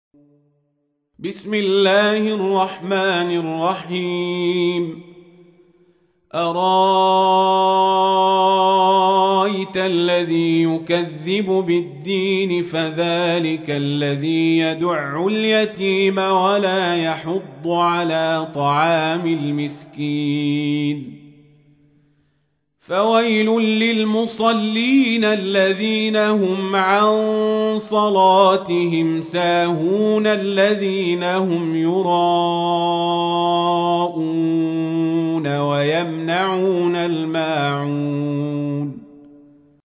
تحميل : 107. سورة الماعون / القارئ القزابري / القرآن الكريم / موقع يا حسين